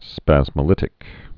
(spăzmə-lĭtĭk)